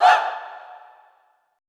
Index of /90_sSampleCDs/Best Service - Extended Classical Choir/Partition I/FEM SHOUTS
FEM HAH   -L.wav